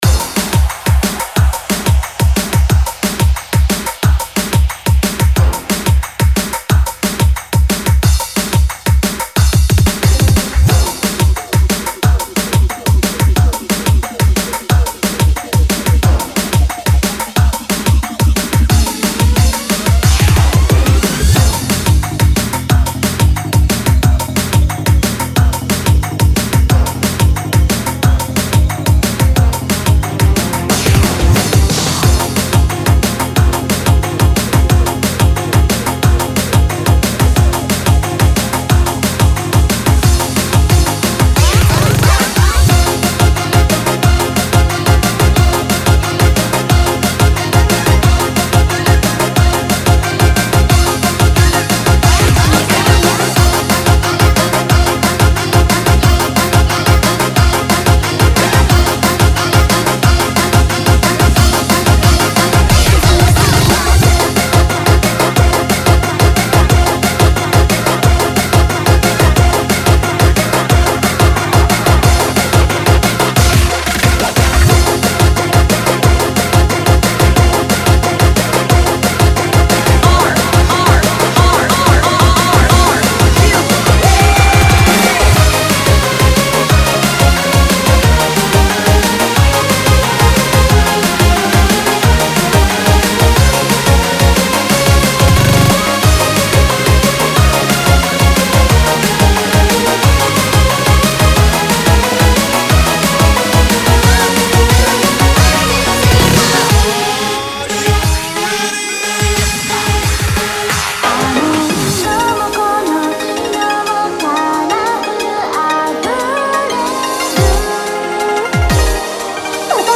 # Funkot # jerseyclub # Hardcore # Drum and Bass